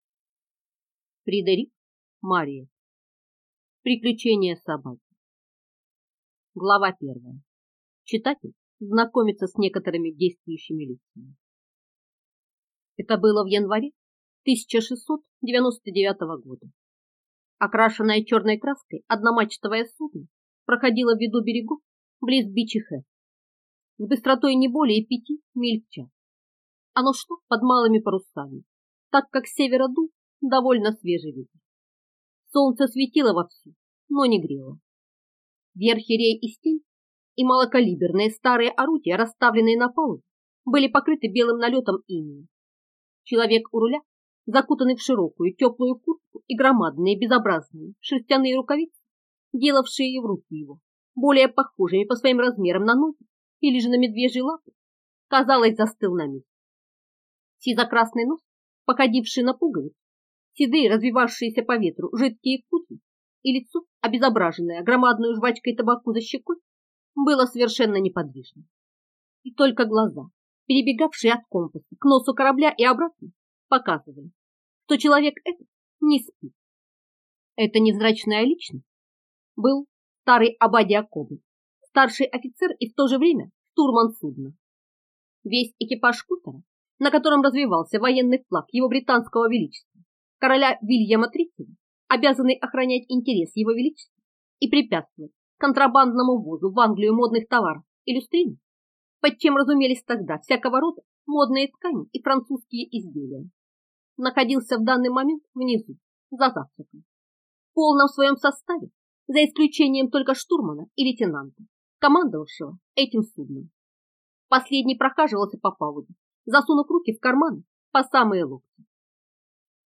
Аудиокнига Приключение собаки | Библиотека аудиокниг